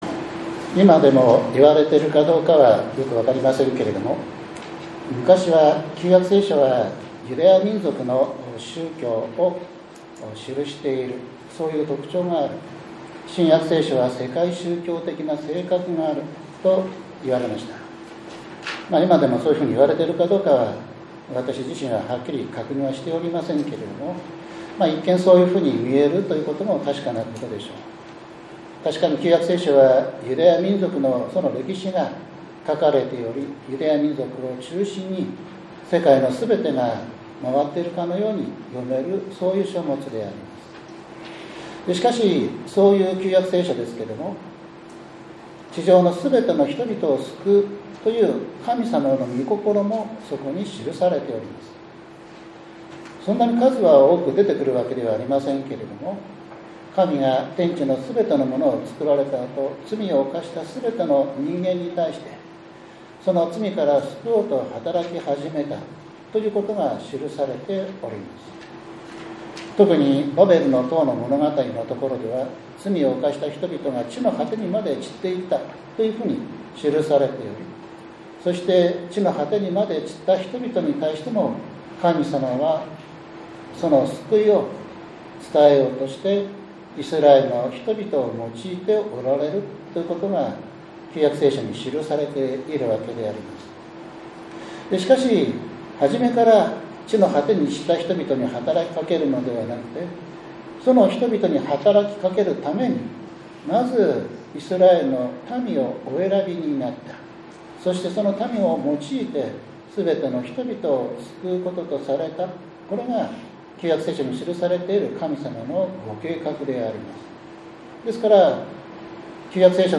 １２月８日（日）降誕節第２主日礼拝 イザヤ書９章１節～６節 ローマの信徒への手紙１５章２０節～２８節